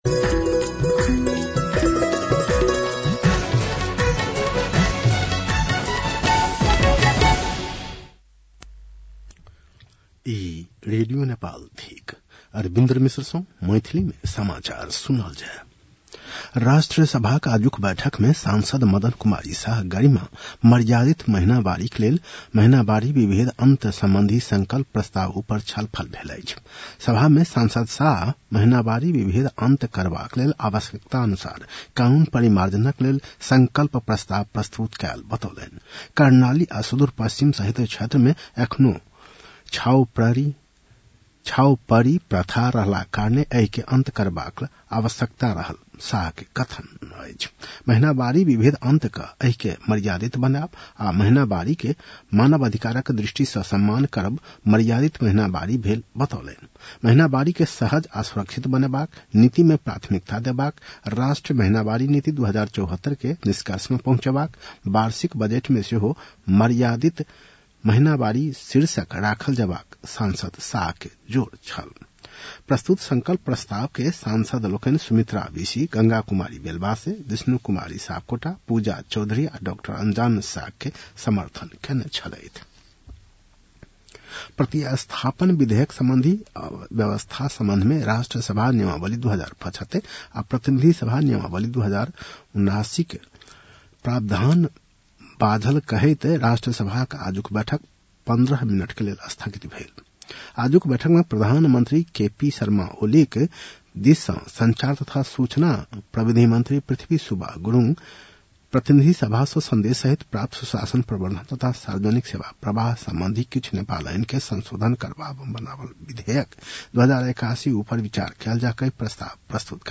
मैथिली भाषामा समाचार : ८ चैत , २०८१